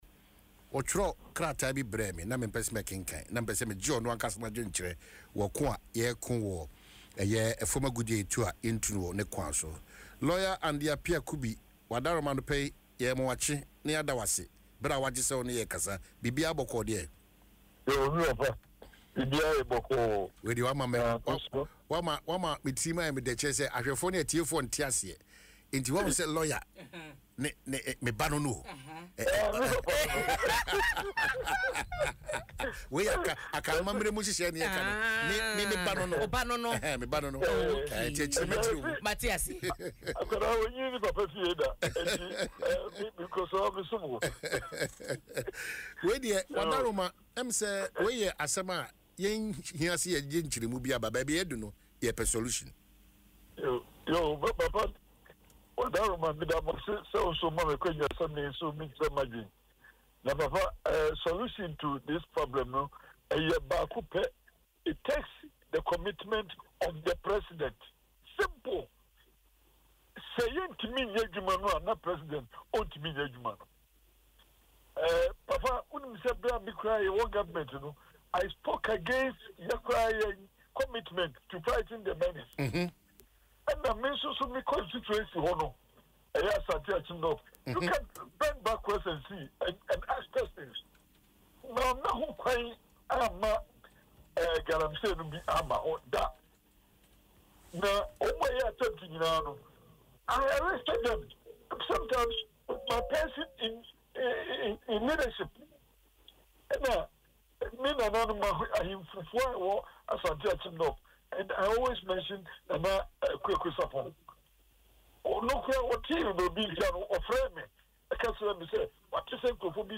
Speaking on Adom FM’s Dwaso Nsem, Mr. Appiah-Kubi recounted his personal experience in combating galamsey during his tenure as MP, stressing that political will at the highest level is critical to ending the practice.